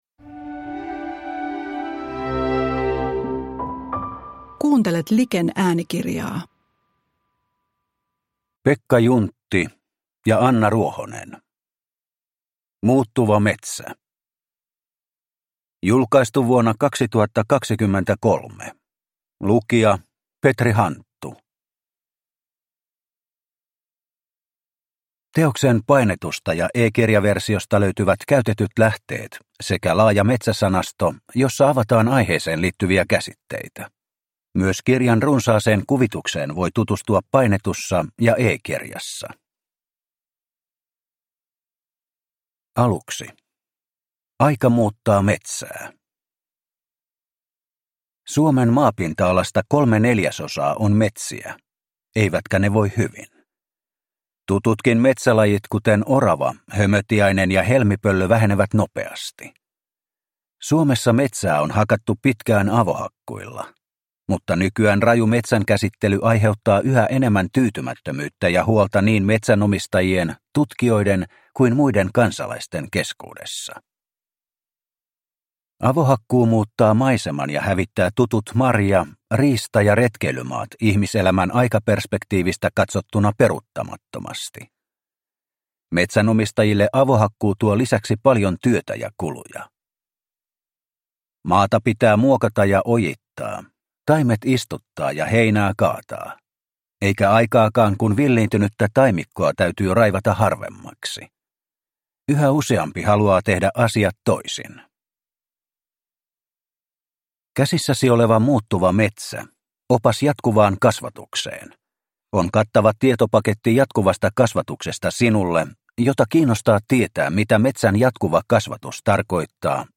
Muuttuva metsä – Ljudbok – Laddas ner